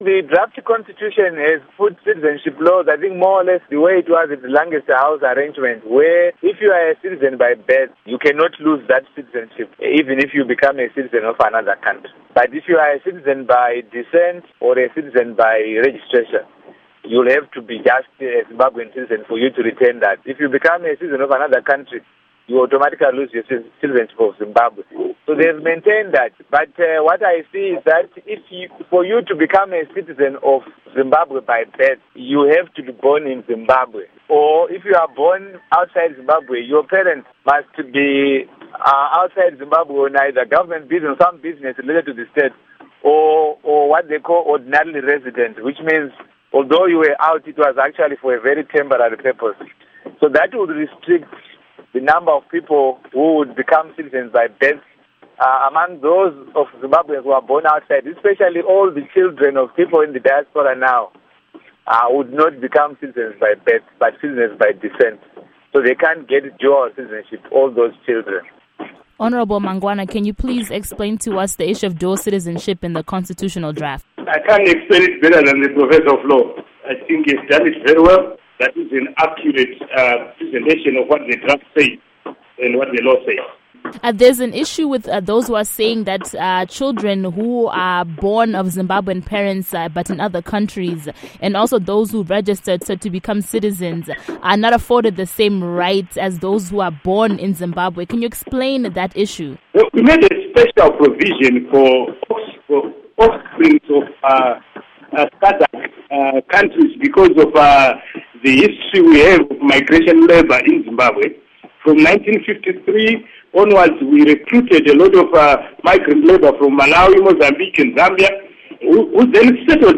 Panel With Munyaradzi Paul Mangwana and Lovemore Madhuku